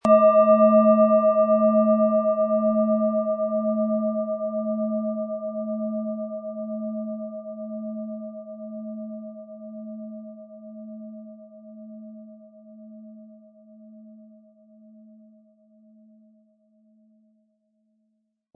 Planetenschale® antik Sinnlich Sein und Fühlen & Ausgeglichen fühlen mit Eros, Ø 12,3 cm, 180-260 Gramm inkl. Klöppel
Durch die traditionsreiche Fertigung hat die Schale vielmehr diesen kraftvollen Ton und das tiefe, innere Berühren der traditionellen Handarbeit
Diese antike Klangschale hat an der Oberfläche Patina oder Altersflecken, das den Klang in keinster Weise beeinträchtigt.
MaterialBronze